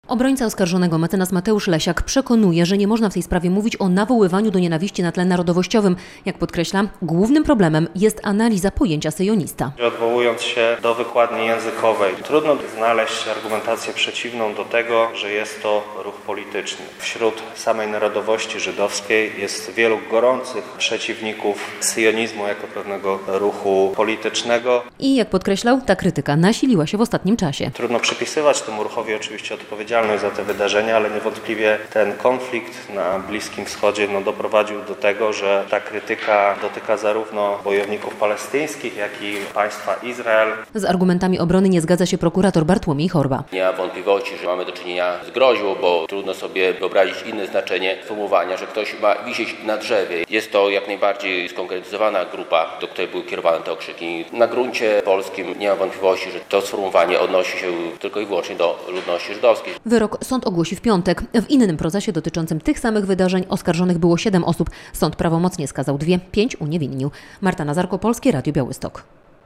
Zakończył się proces apelacyjny ws. nawoływania do nienawiści podczas marszu ONR w Białymstoku